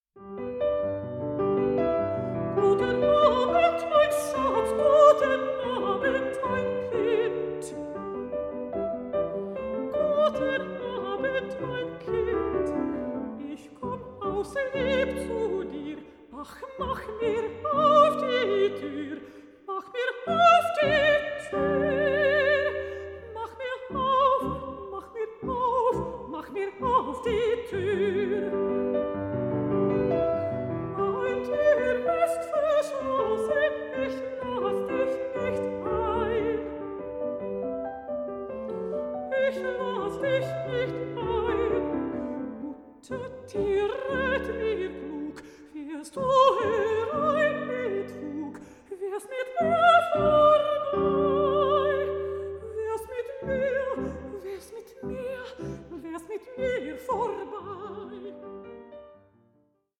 pianist
mezzo-soprano